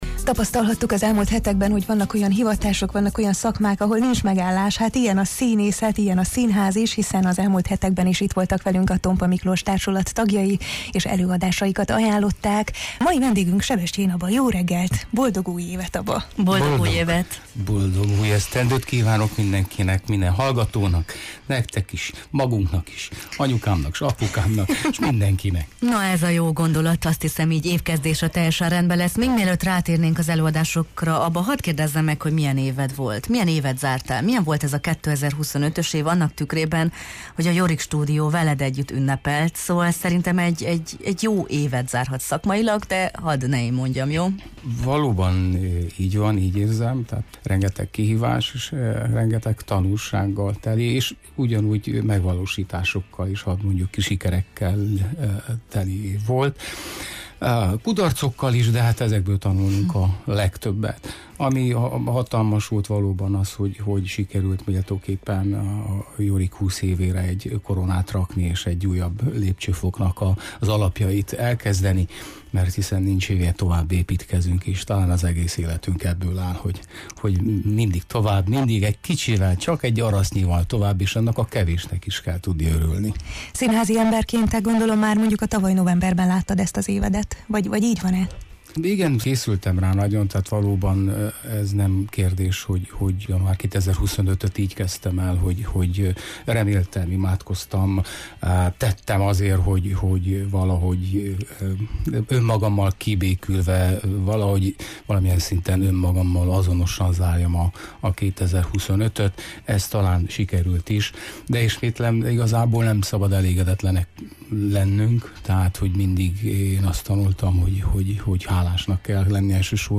a Jó reggelt, Erdély! című műsor vendégeként